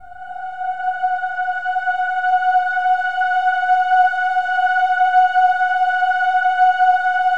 OH-AH  F#5-R.wav